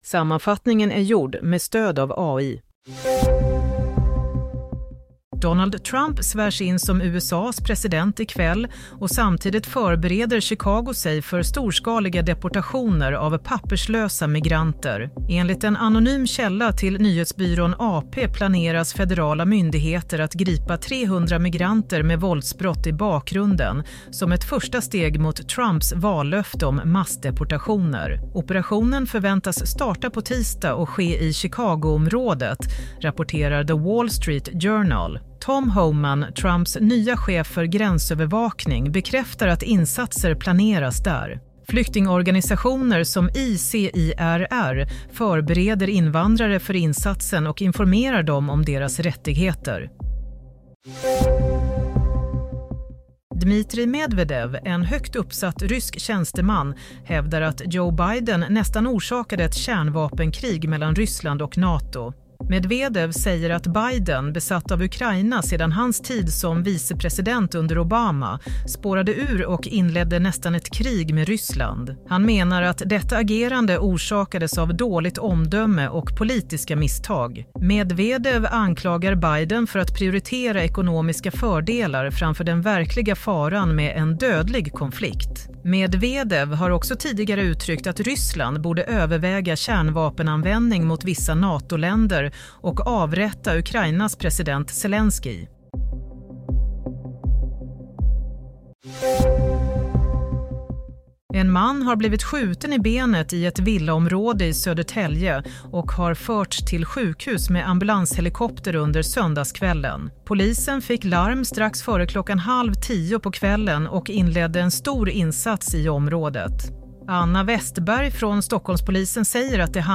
Play - Nyhetssammanfattning - 20 januari 07:00 - Archive FM